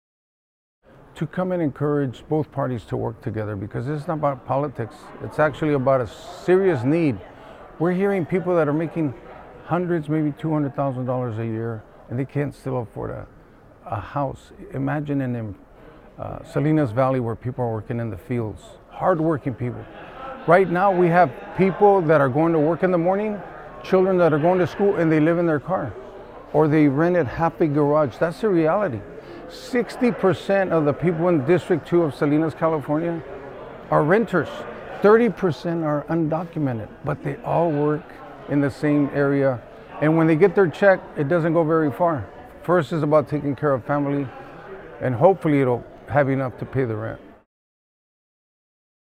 For radio news outlets who would like to air this story, the following links are soundbites of the State Legislative Issues Day in English and Spanish
Tony Barrera, Salinas City Council Member (English) :49